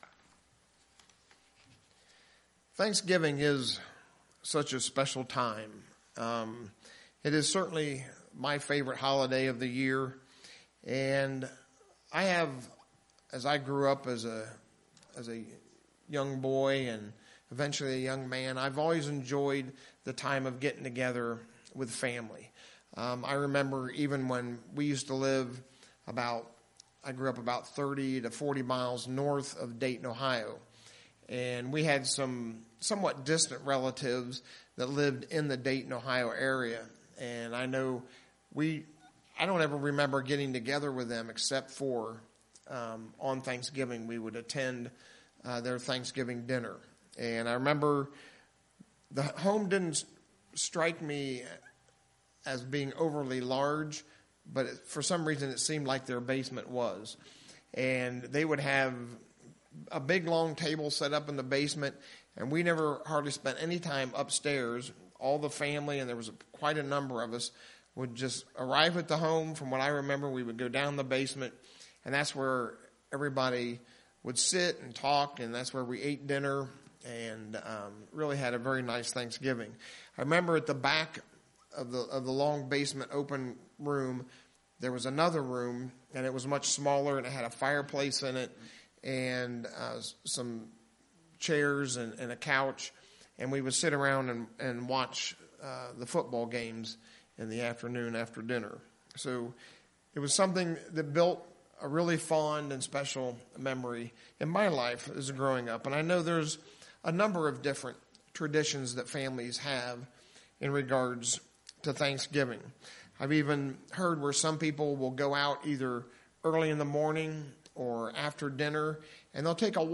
In Psalm 136, the Psalmist explores just a few of God's amazing works, but it is an excellent reminder of our many blessings and wonders from God! In today's sermon we will review God's wonders as described in Psalm 136.